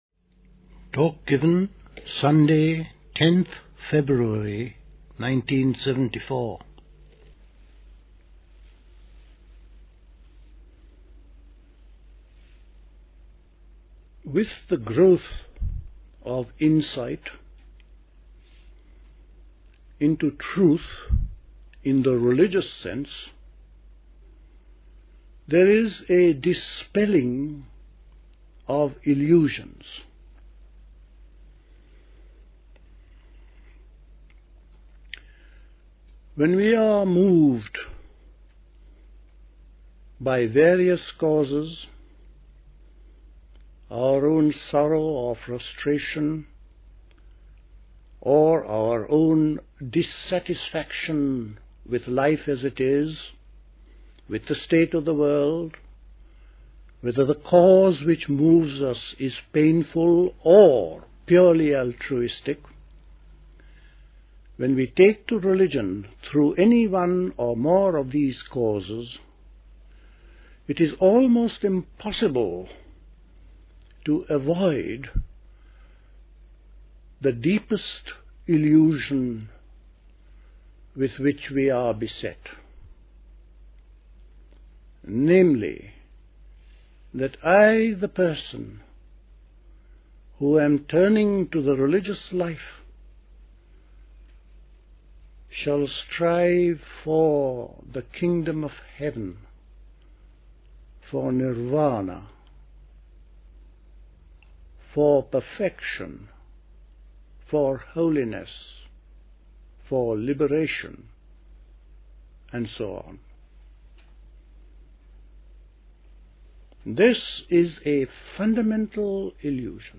A talk
at Dilkusha, Forest Hill, London on 10th February 1974